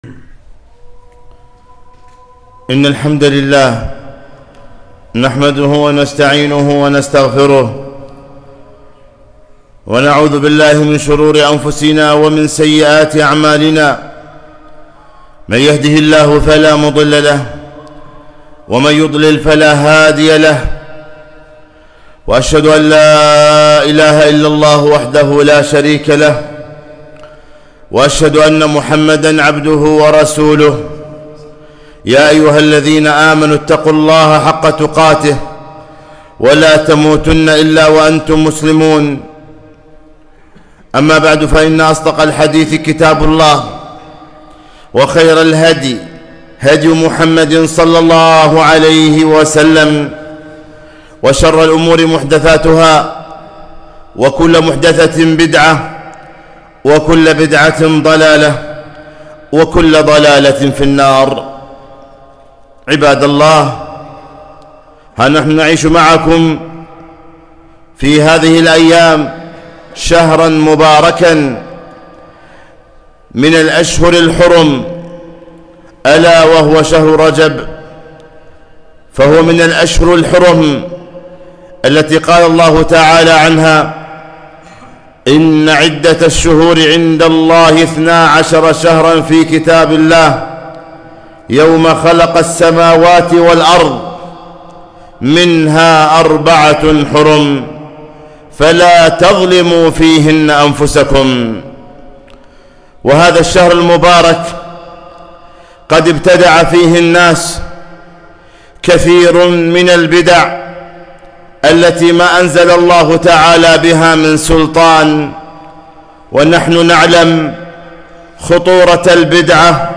خطبة - بدع شهر رجب